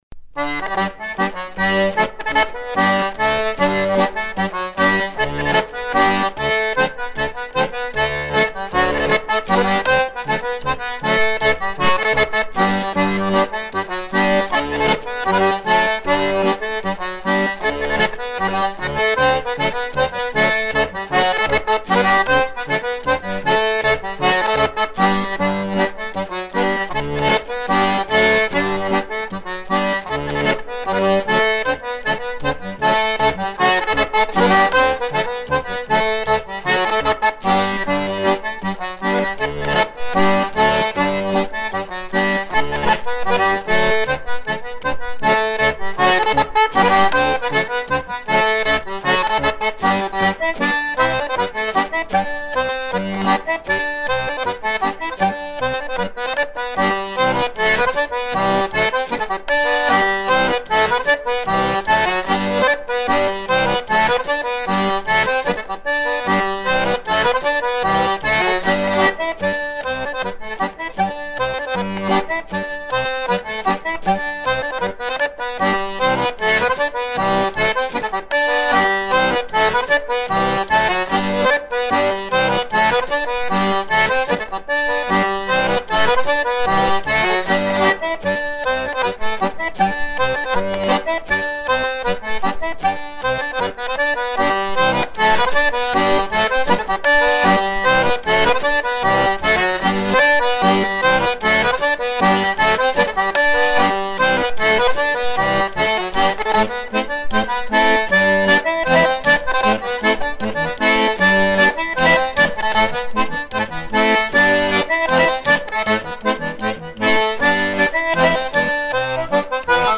Trois airs à danser la ridée recueillis dans le Morbihan
ridée 6 temps
Pièce musicale éditée